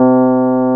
HARD RHODES.wav